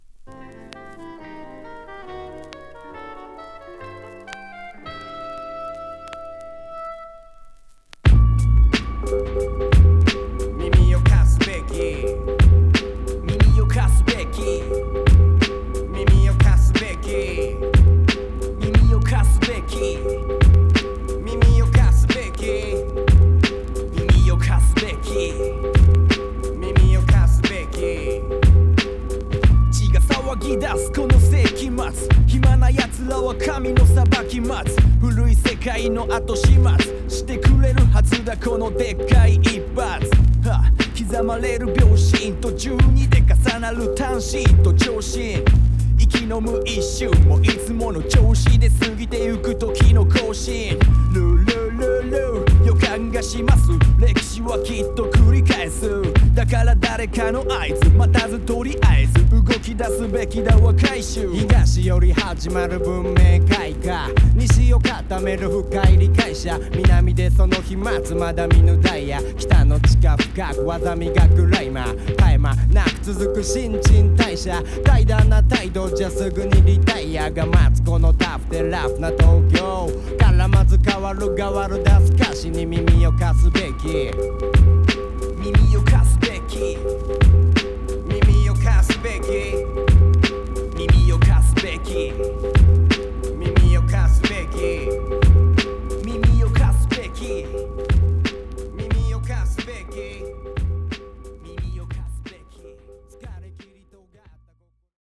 血が騒ぐ様な太いウッドベースのループが何とも言えない雰囲気を醸し出した